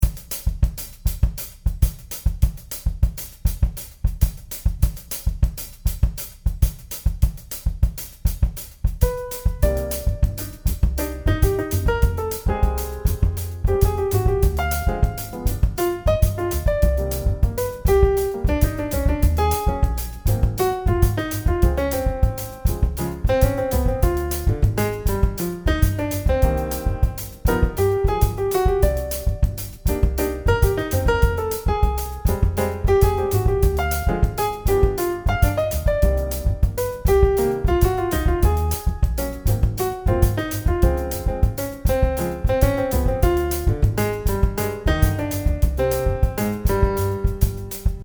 Si può notare che ho aggiunto un pedale introduttivo con l’accordo di dominante della tonalità, ho mantenuto la stessa struttura armonica dello standard Blue Bossa, ma ho composto una mia melodia.